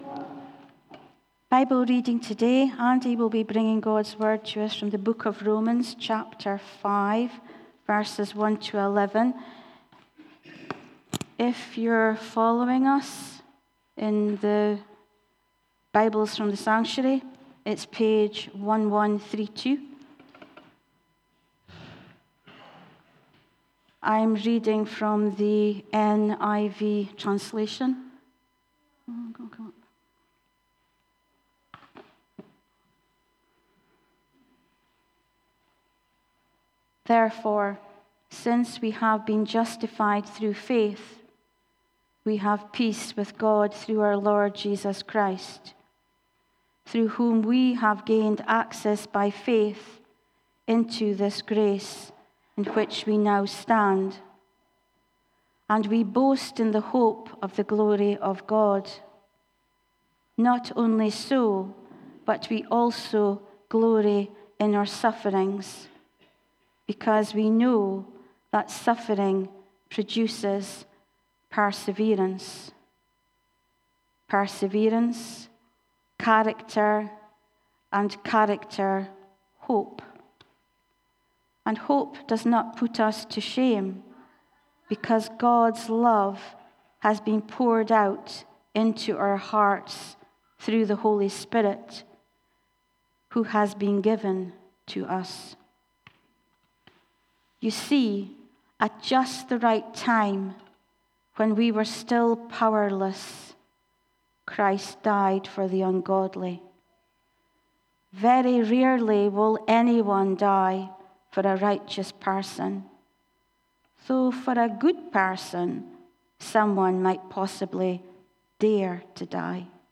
Bridge of Don Baptist Church Sermons